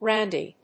/rˈændi(米国英語), ˈrændi:(英国英語)/